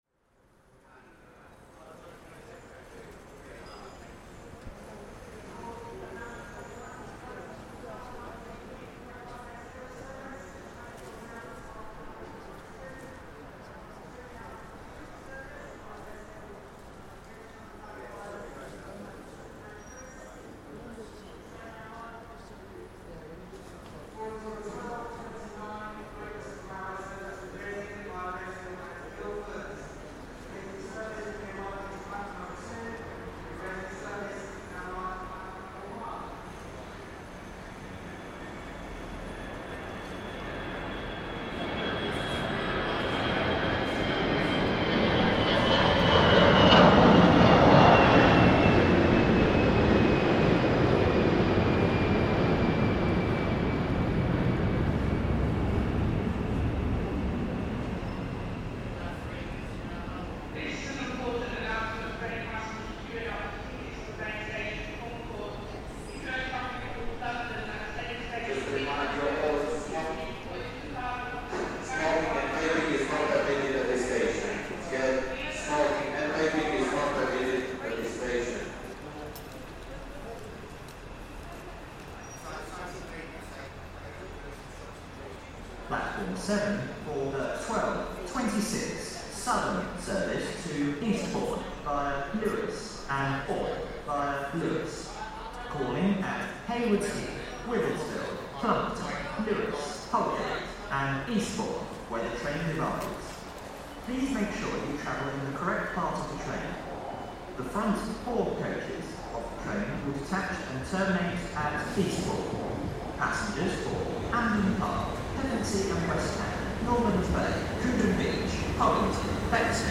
On the newly-refurbished platform 7 at Gatwick Airport, UK, as we hears trains arriving, dropping up hundreds of travellers and holidaymakers excited about embarking on their latest journeys, while every 30 to 60 seconds a plane takes off or lands overhead, making it clear that this can only be a train station directly connected to an airport. Recorded by Cities and Memory.